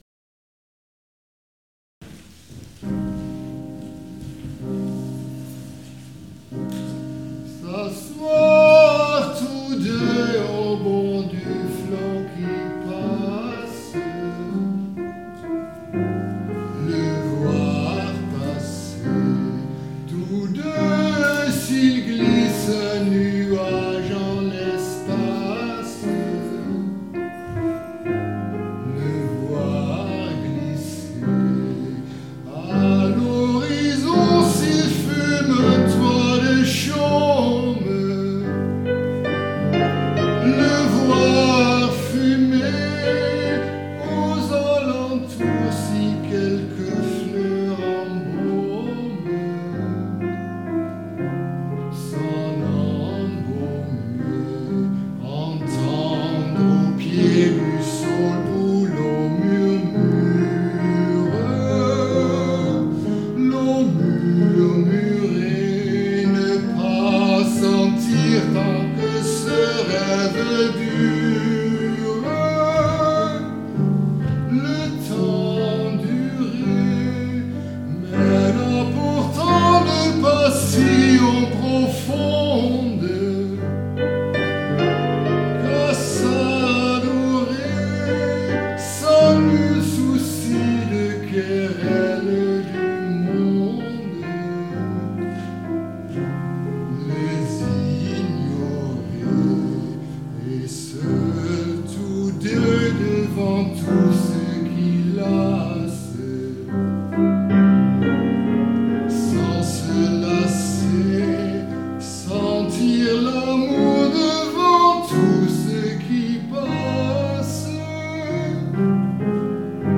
Hier kun je mijn zang beluisteren:
twee stukken met pianobegeleiding